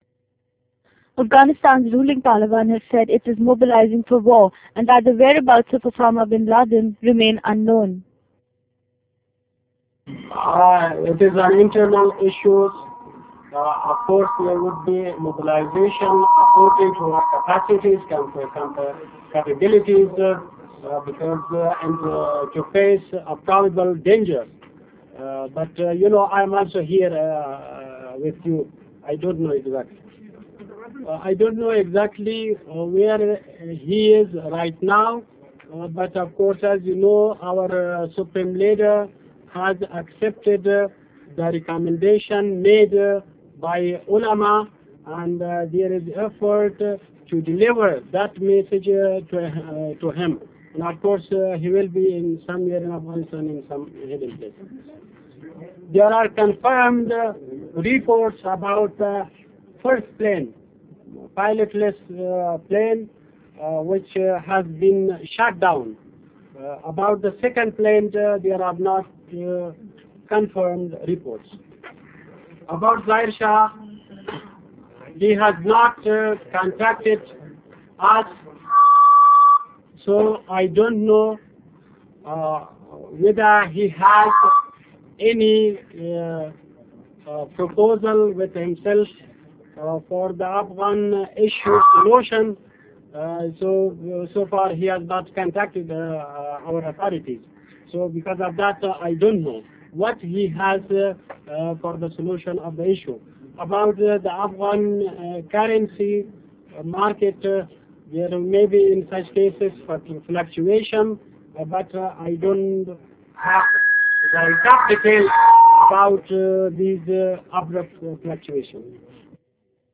The Taliban Ambassador in Islamabad, Mullah Abdul Salaam Zaeef, told a news conference in the Pakistani capital that Kabul was mobilising for war.